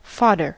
Ääntäminen
Synonyymit Steher Tragwerk Mästung Ääntäminen Tuntematon aksentti: IPA: /mast/ Haettu sana löytyi näillä lähdekielillä: saksa Käännös Ääninäyte Substantiivit 1. fodder US 2. pylon 3. mast US Artikkeli: der .